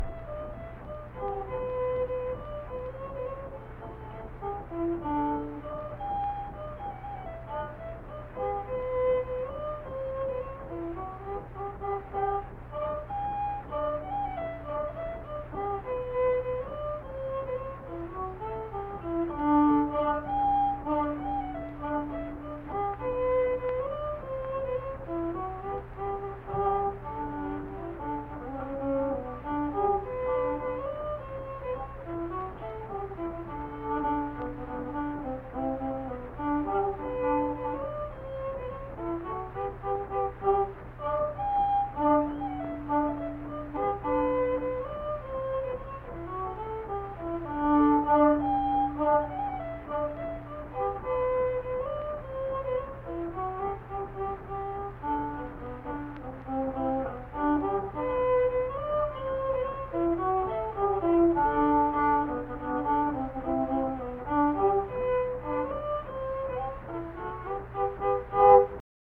Unaccompanied fiddle music performance
Verse-refrain 2(2).
Instrumental Music
Fiddle